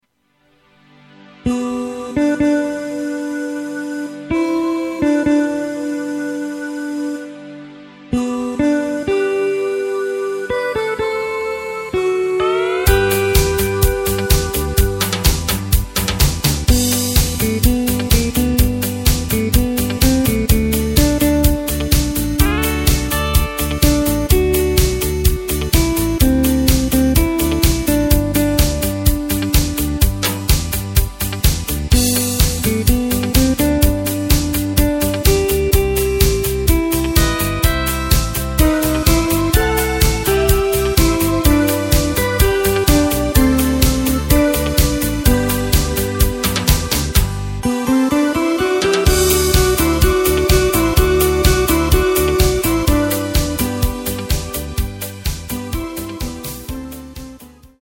Takt:          4/4
Tempo:         126.00
Tonart:            G
Schlager aus dem Jahr 1985!